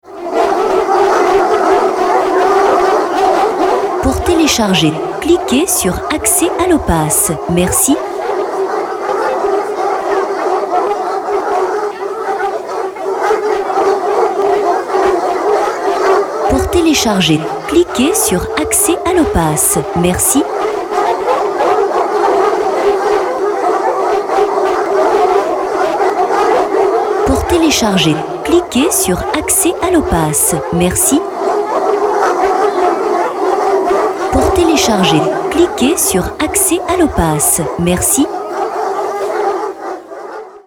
MEUTE DE CHIENS